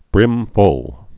(brĭmfl)